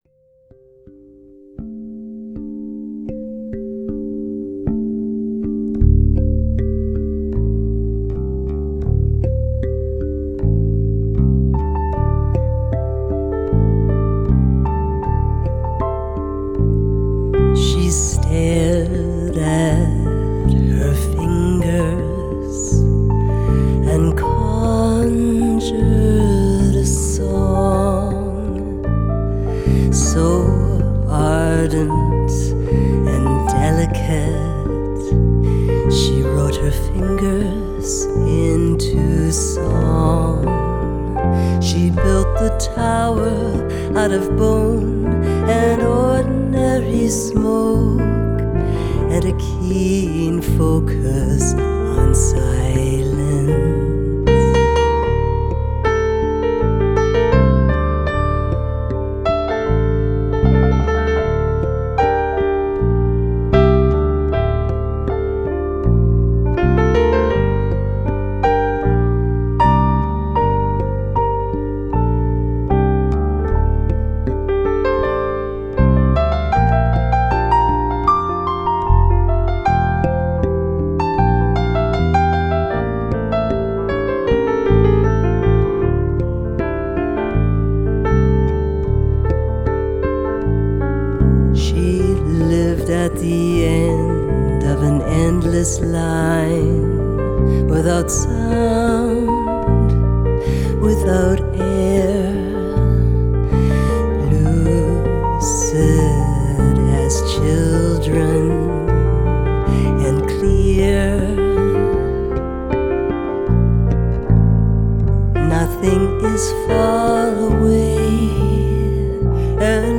saxophone
mandolin